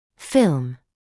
[fɪlm][филм]пленка; снимок (в т.ч. рентгеновский)